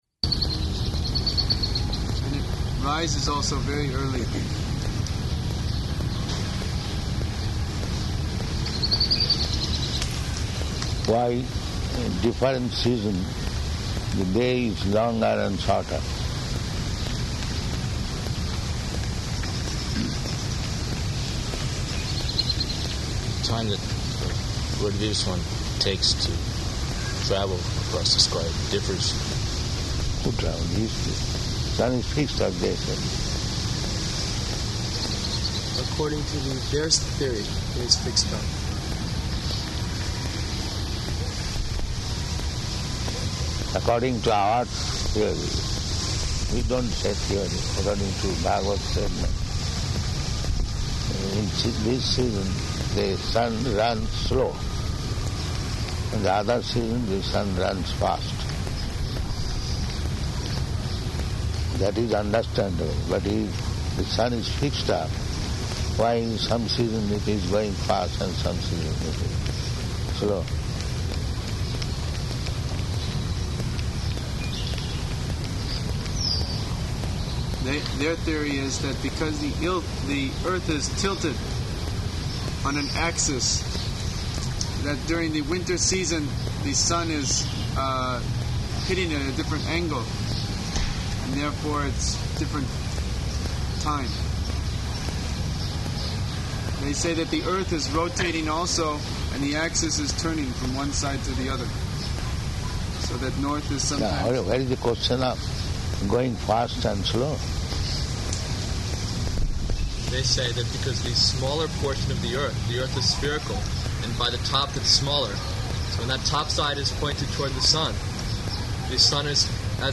Garden Conversation
-- Type: Conversation Dated